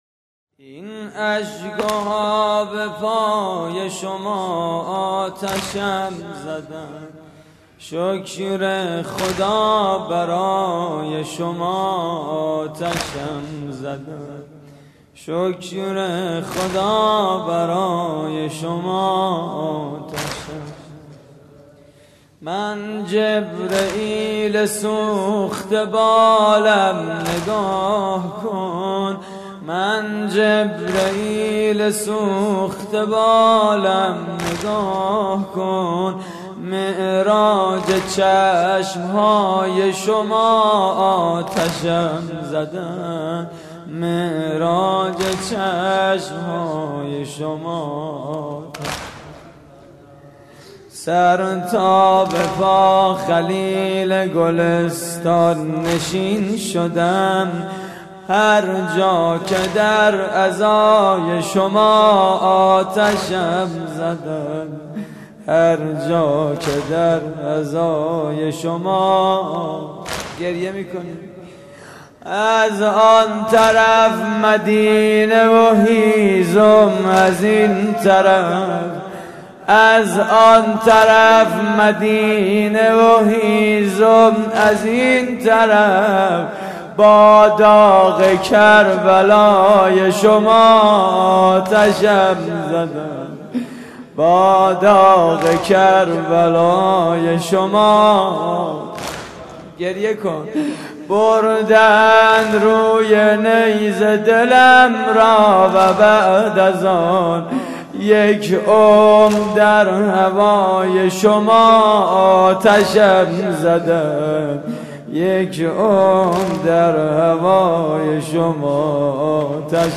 مراسم عزاداری شب دوازدهم (محرم 1433)